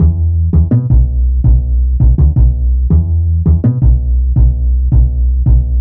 Index of /90_sSampleCDs/Zero-G - Total Drum Bass/Instruments - 1/track02 (Bassloops)